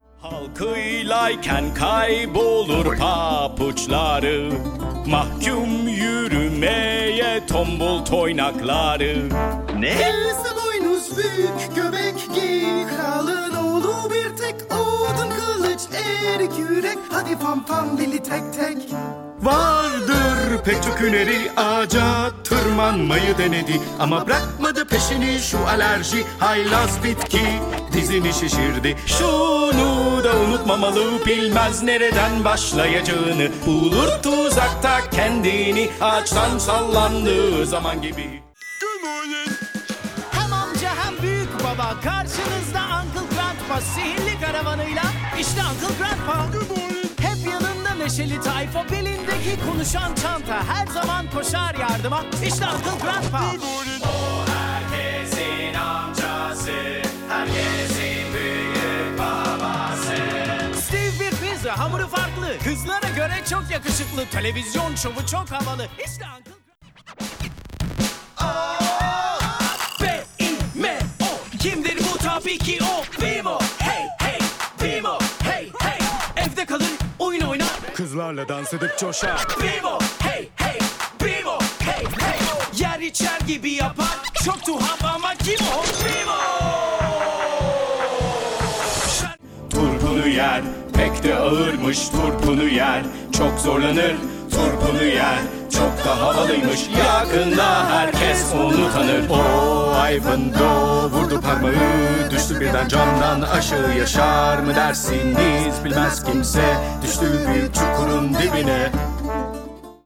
Cartoon Jingles Showreel
Male
English with International Accent
Middle Eastern
Playful
Smooth
Bright
Friendly